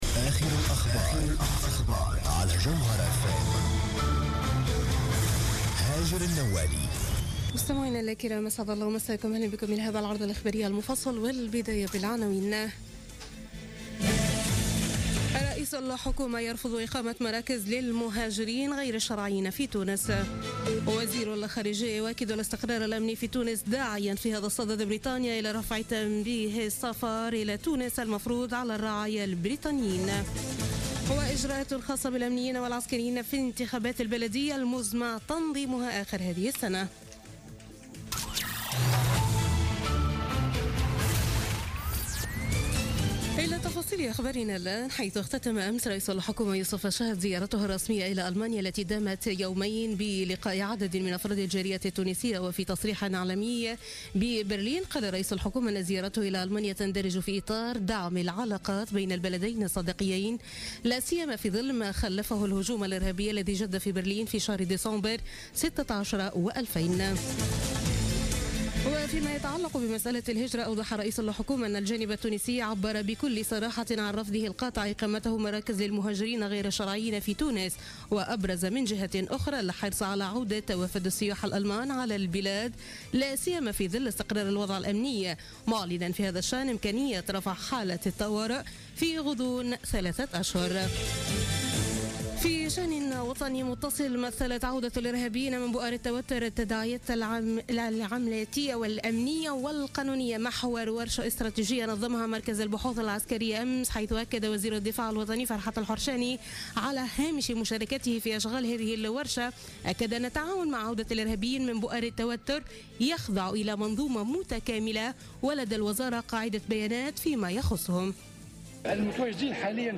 نشرة أخبار منتصف الليل ليوم الخميس 16 فيفري 2017